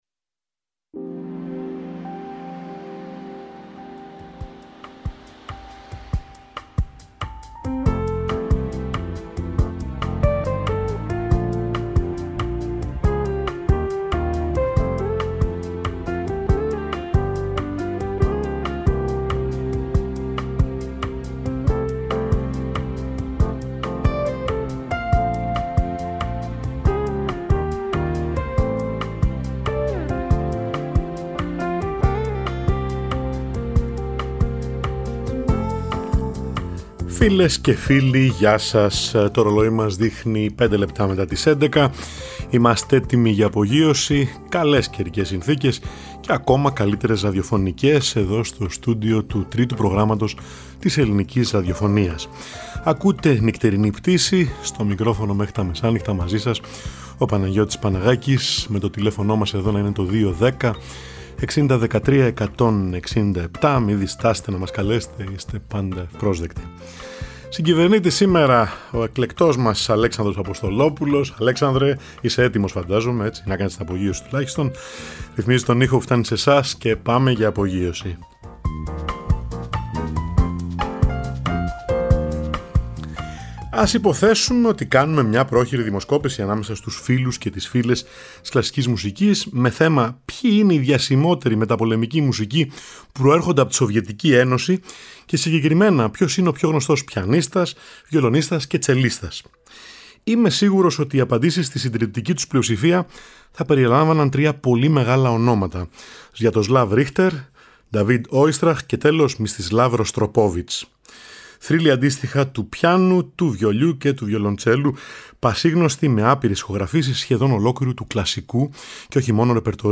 πιάνο
βιολί
τσέλο